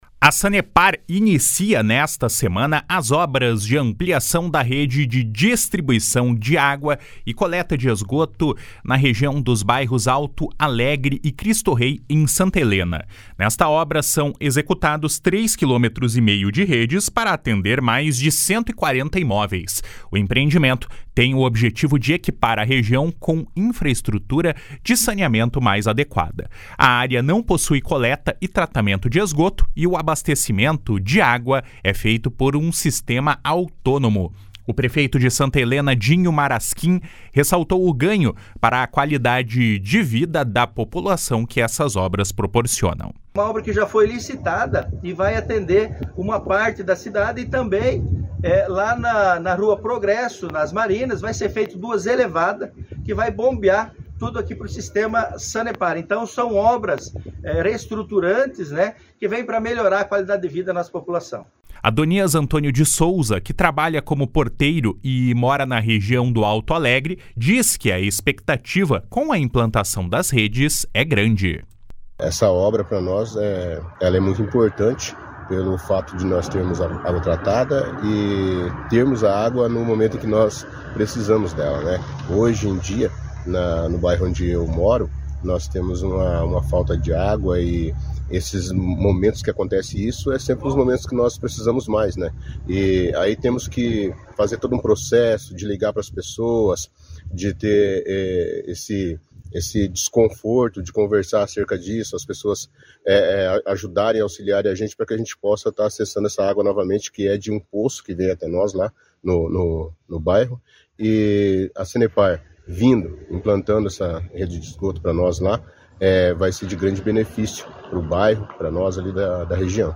O prefeito de Santa Helena, Dinho Maraskin, ressaltou o ganho para a qualidade de vida da população que essas obras proporcionam.